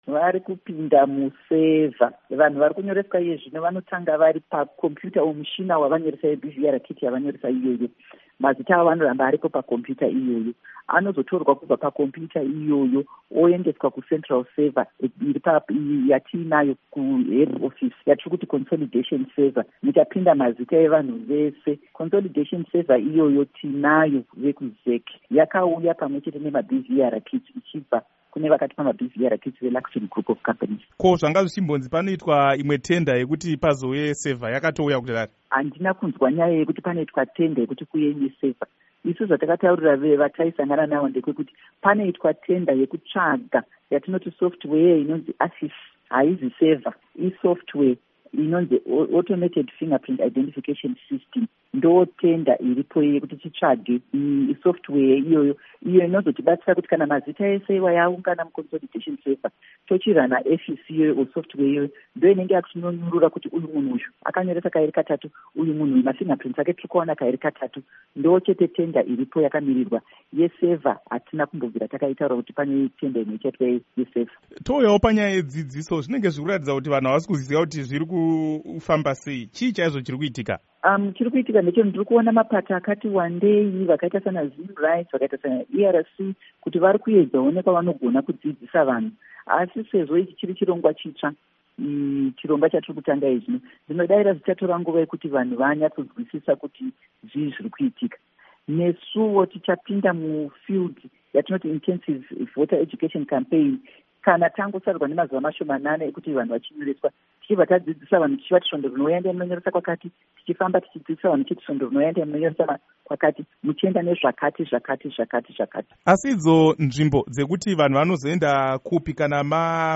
Hurukuro naAmai Rita Makarau